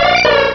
cries
-Replaced the Gen. 1 to 3 cries with BW2 rips.